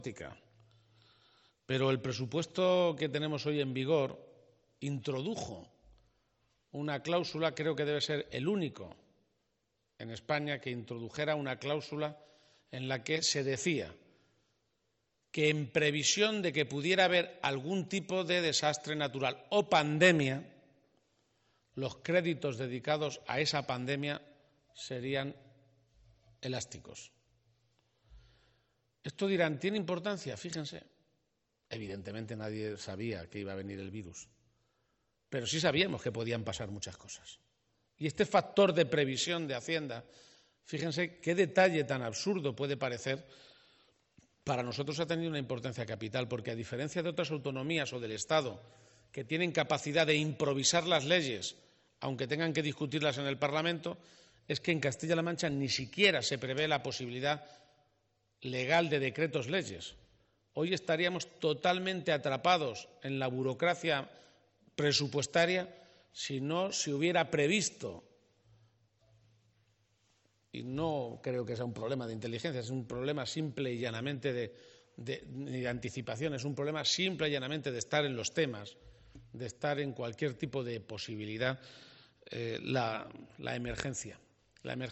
>> En la firma del Plan de Medidas Extraordinarias para la Recuperación Económica